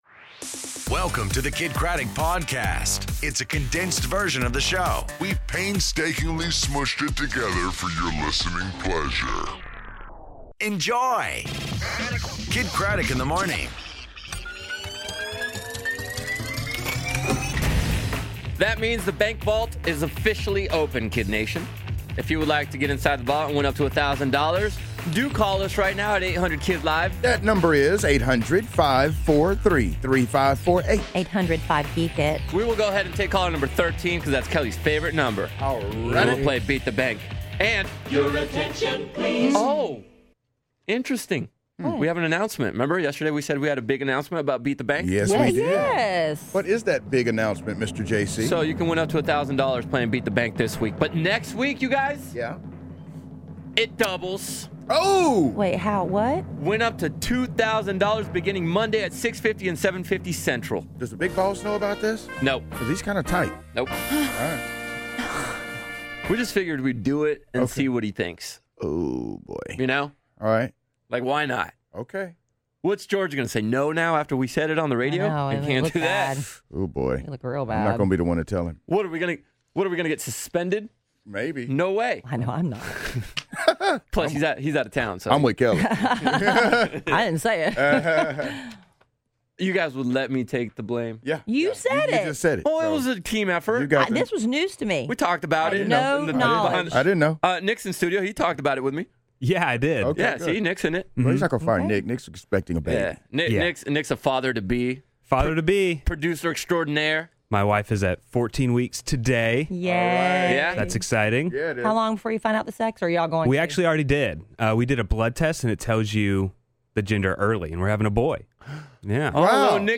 Tinder Tuesday, Keke Palmer In Studio, And Tina Fey Throws Shade At Taylor Swift